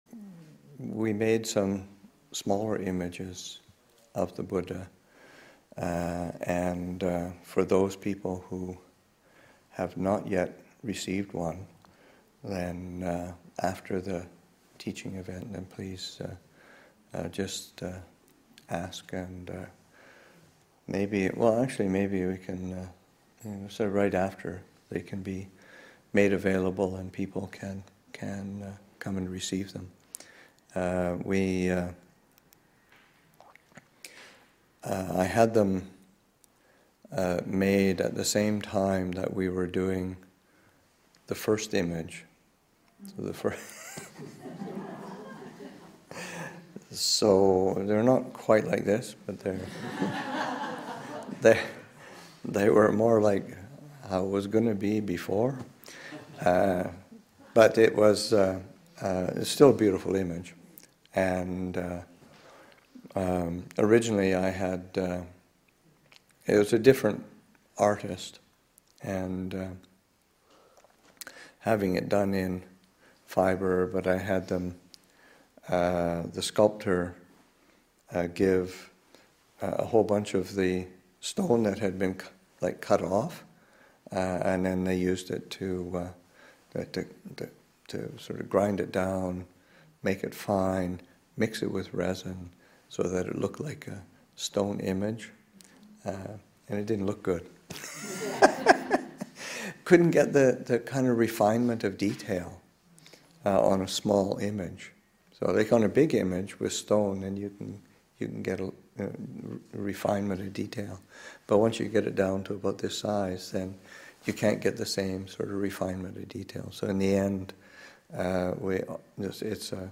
Story: The making of the small replicas of the main Buddha image.